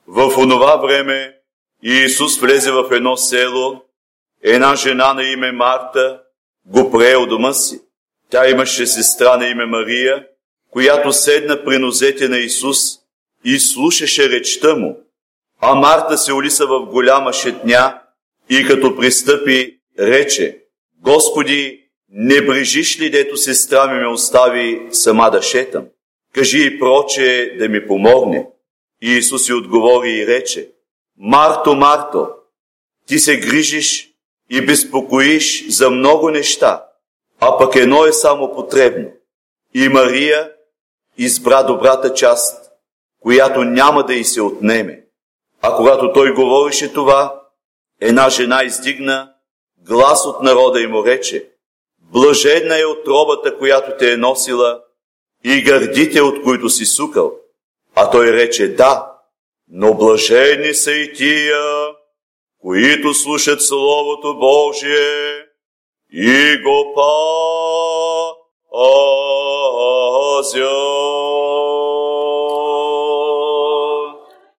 Евангелско четиво